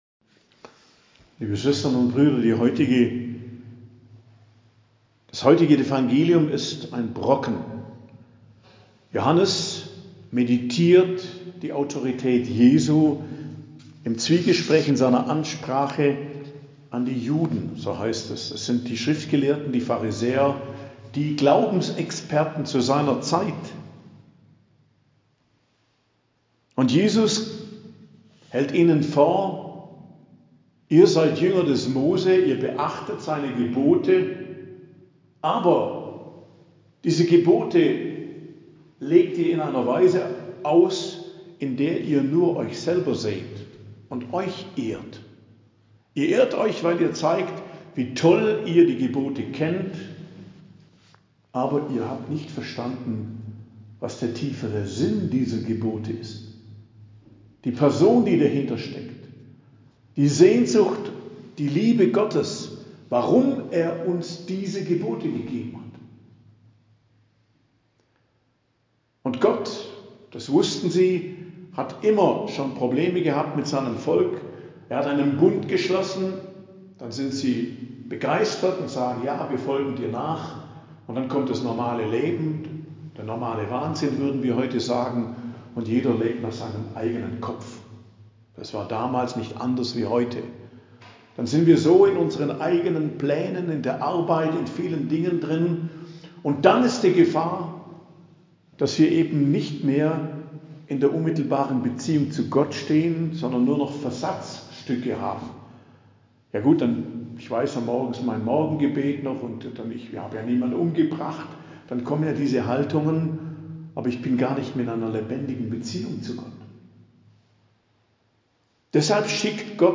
Predigt am Donnerstag der 4. Woche der Fastenzeit, 14.03.2024